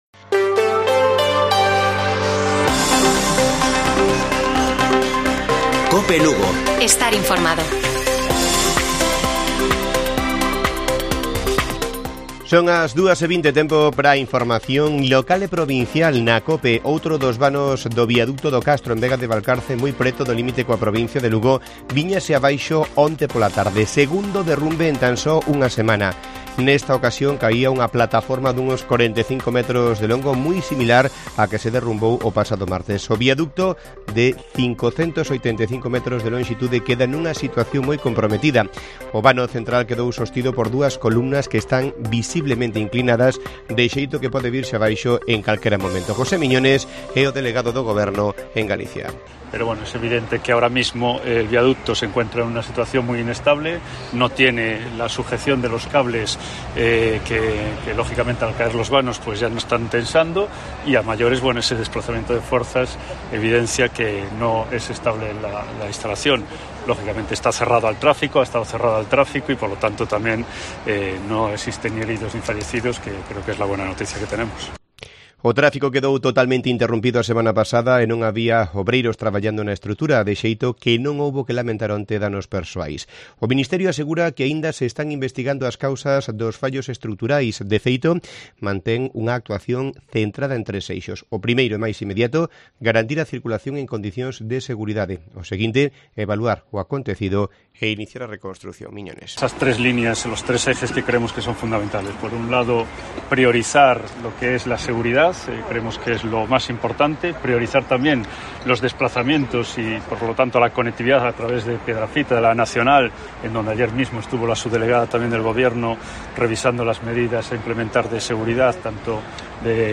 Informativo Mediodía de Cope Lugo. 17 DE JUNIO. 14:20 horas